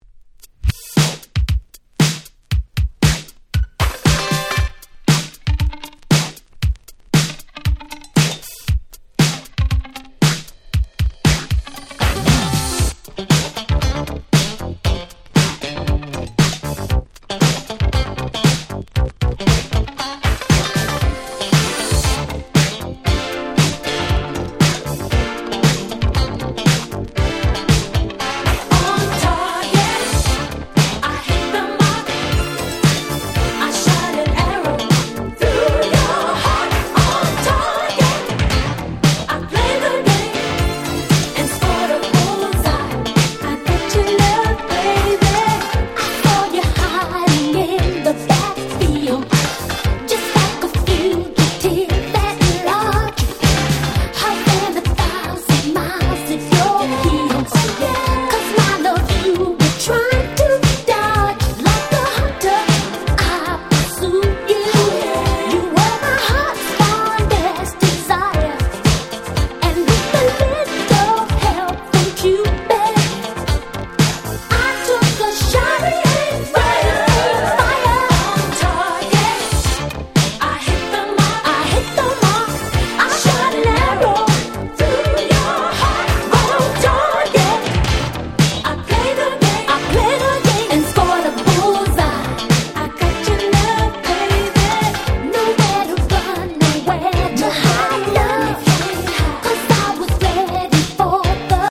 83' Super Nice Boogie / Disco !!